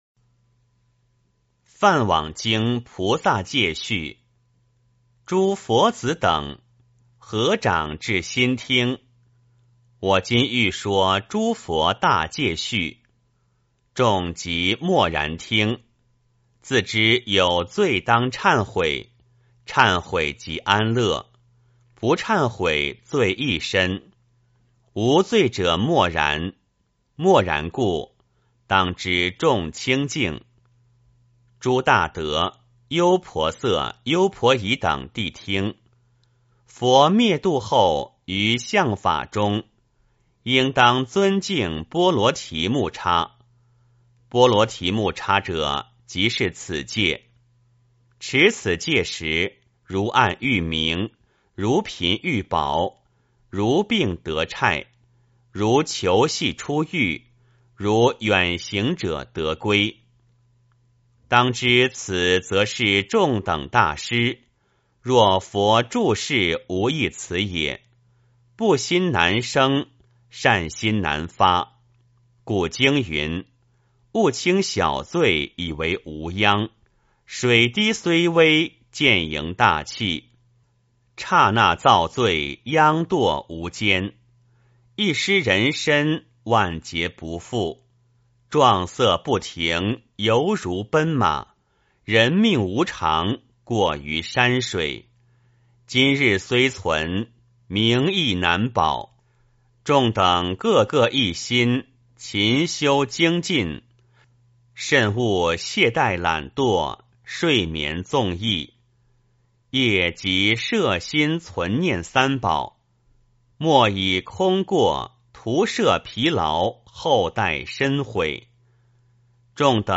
梵网经-梵网经菩萨戒序 - 诵经 - 云佛论坛